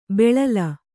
♪ beḷala